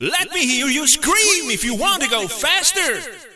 scream-faster_azW9rik.mp3